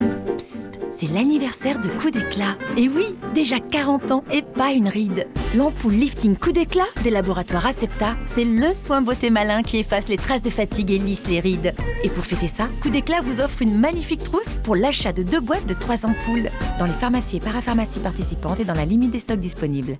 complice // fraîche
Spot-Radio-Coup-déclat-Complice-fraîche-copie.mp3